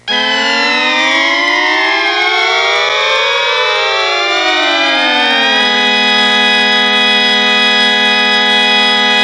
Church Organ Intro Sound Effect
Download a high-quality church organ intro sound effect.
church-organ-intro.mp3